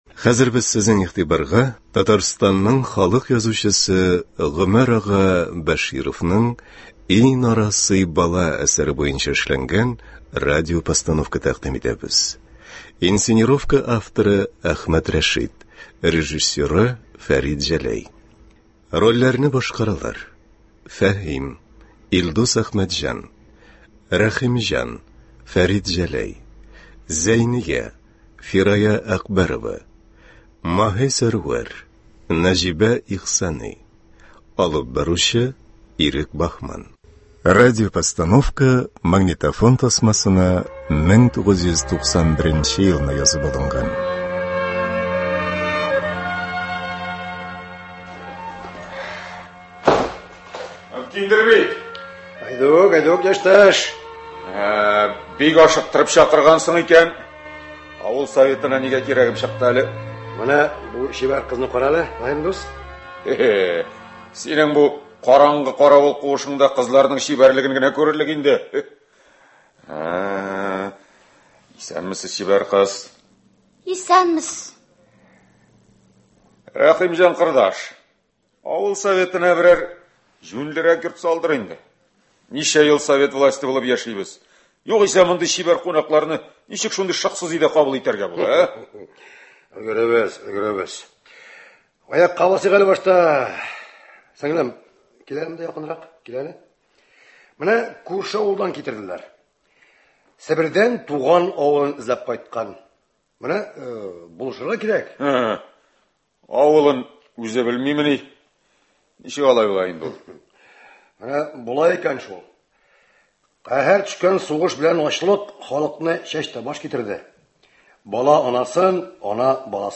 Игътибарыгызга Татарстанның халык язучысы Гомәр ага Бәшировның “И, нарасый бала!..” әсәре буенча эшләнгән радиопостановка тәкъдим итәбез. 1991 елда Татарстан радиосы студиясендә магнитофон тасмасына язып алынган бу радиотамашаның инсценировка авторы – Әхмәт Рәшит.